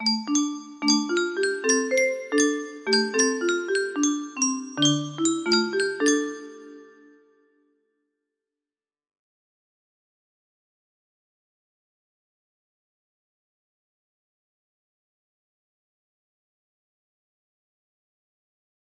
demo music box melody